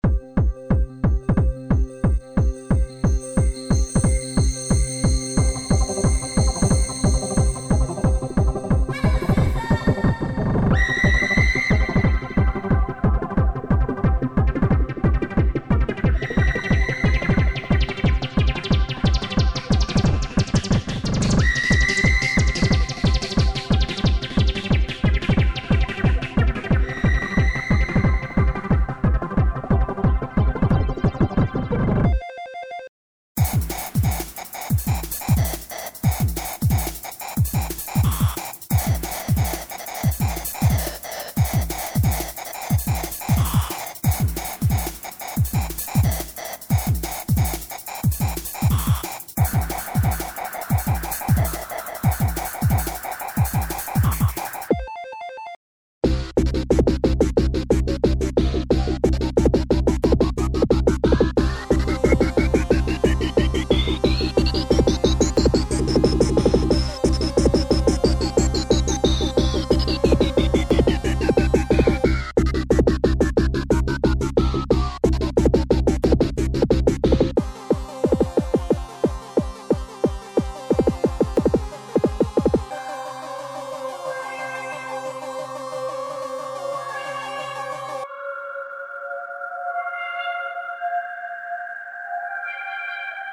多分テクノ系です。